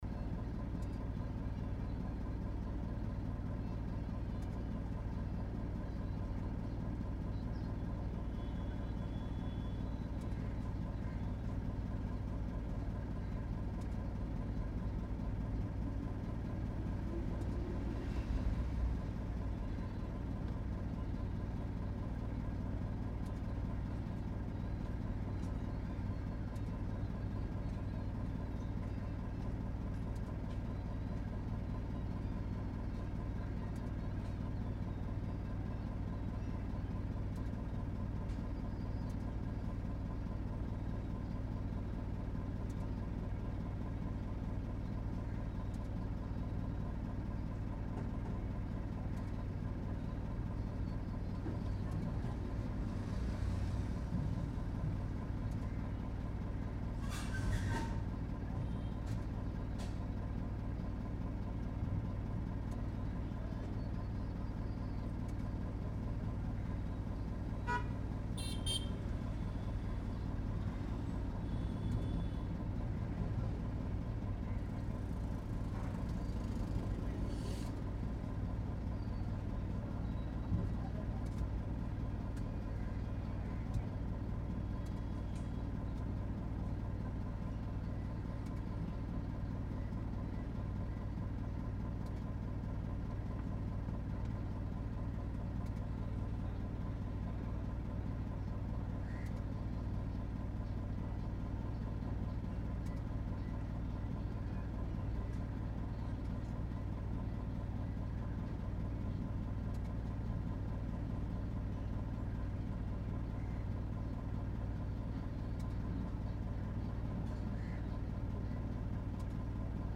JCB Work-AMB-033
A powerful and realistic ambience capturing the heavy mechanical movements of an active JCB machine. This sound includes engine revs, hydraulic arm lifts, bucket digging, soil shifting, and metal clanks recorded in a natural outdoor construction environment. The texture is rich, gritty, and highly detailed, making it ideal for adding industrial realism to visual scenes. Clean layers of machinery hum, mechanical pressure, and ground impact enhance the authenticity without overpowering the main audio.
JCB / Excavator
High Mechanical
Machinery / Construction
Outdoor Industrial Wide Field